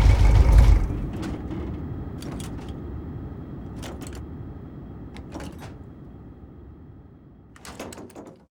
car-engine-stop-1.ogg